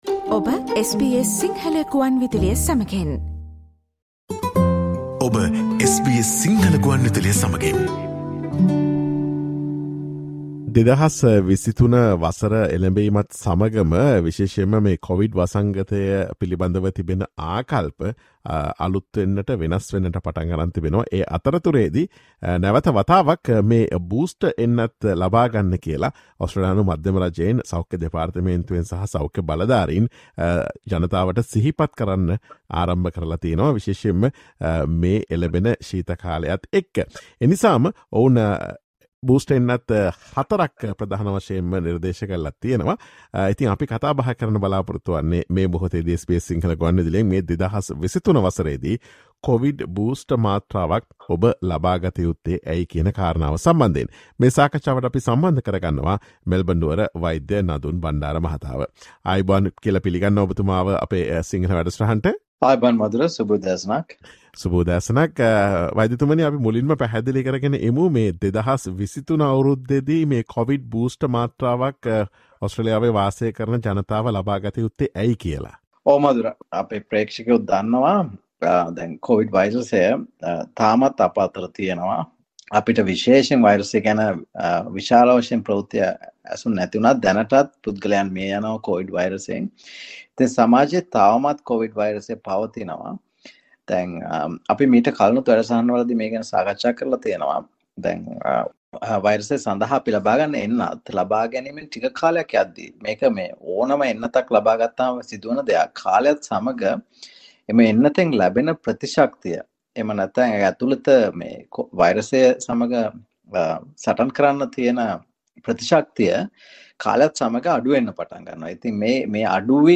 ඔස්ට්‍රේලියාවේ වෙසෙන ඔබ 2023 වසරේදී කොවිඩ් බූස්ටර් මාත්‍රාවක් ලබා ගත යුත්තේ ඇයි සහ ලබාගත යුත්තේ කුමන බූස්ටර් මාත්‍රාවද යන්න පිළිබඳ SBS සිංහල ගුවන් විදුලිය සිදුකළ සාකච්ඡාවට සවන් දෙන්න.